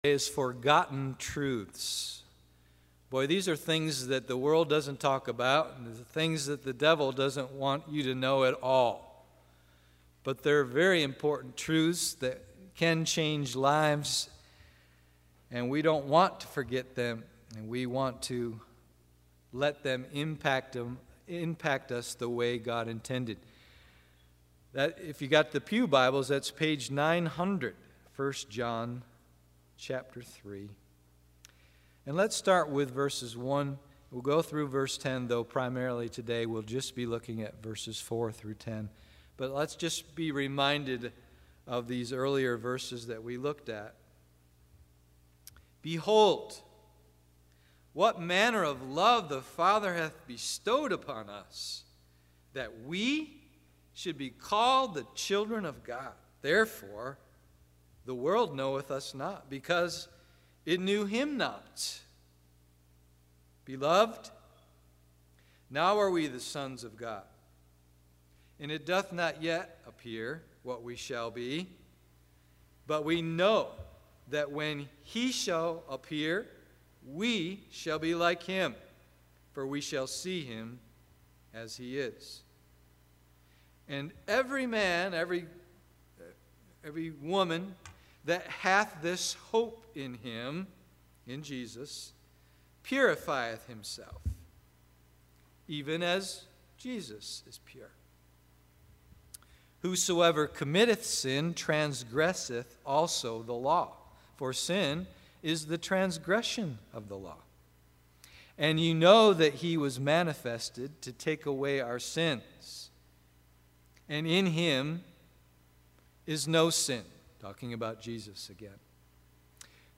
Forgotten Truths AM Service